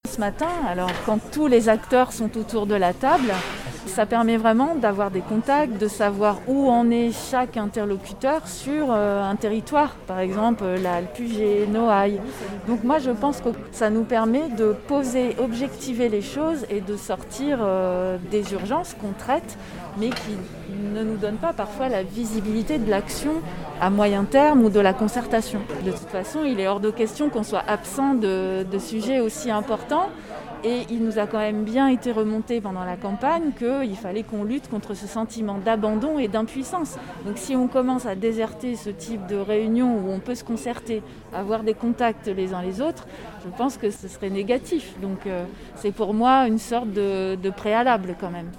sophie_camard_debut_partie_1_son_choix_de_participer.mp3